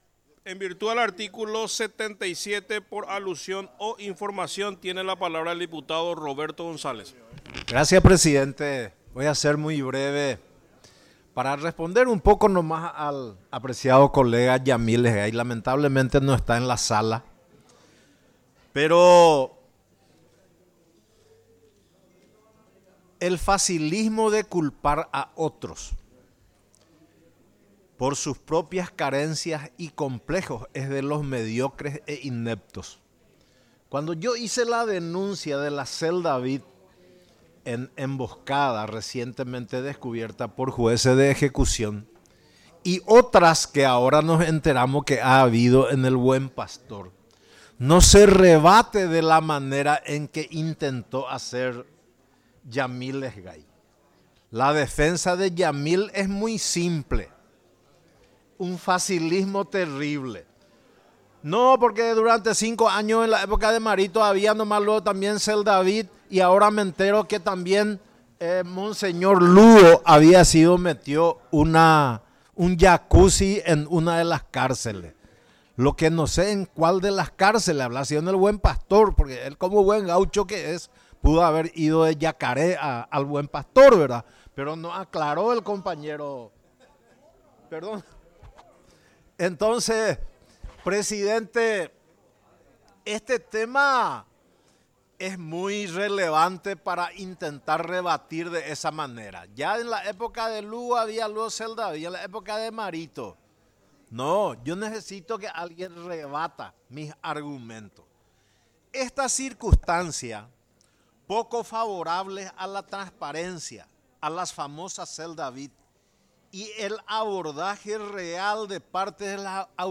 Sesión Extraordinaria, 26 de agosto de 2025
Exposiciones verbales y escritas
10 – Dip Jorge Barressi